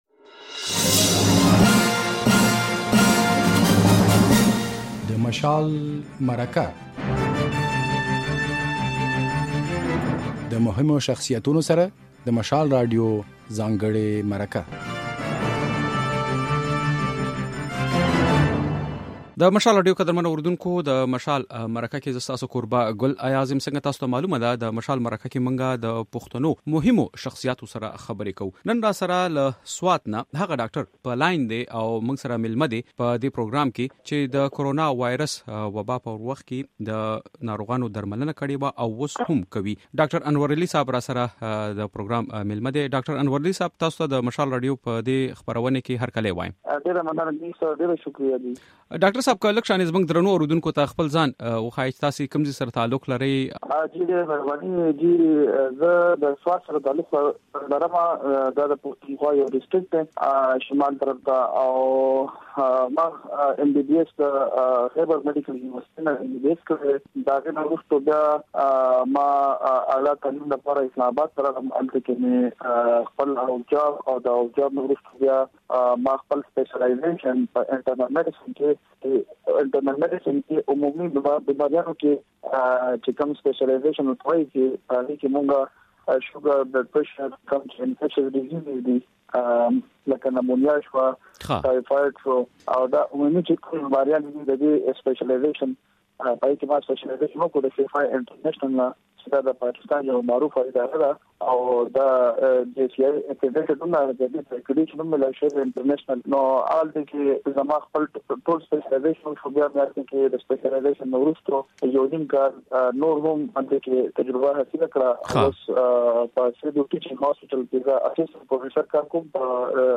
د مشال مرکه